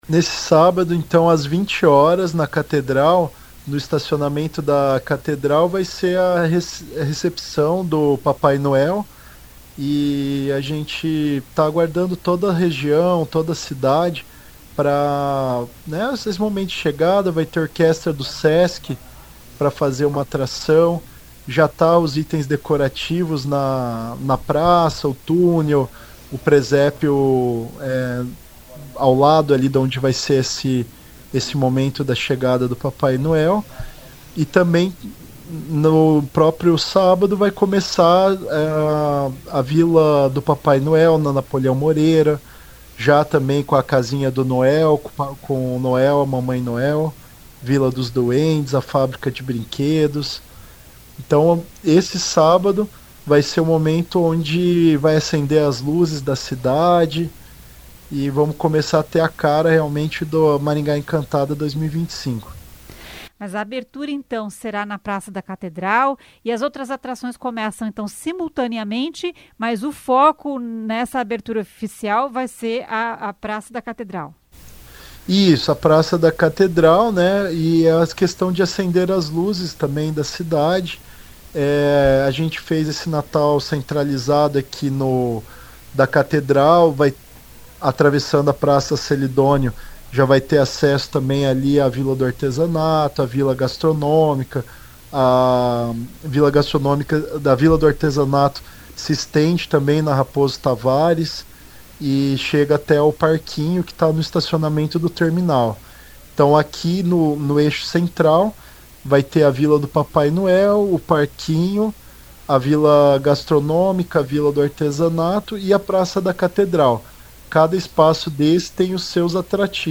A Maringá Encantada começa oficialmente nesse sábado (6), às 20h, na Praça da Catedral, com a chegada do Papai Noel, que vem de carro alegórico. Ouça o que diz o secretário de Aceleração Econômica e Turismo, Annibal Bianchini.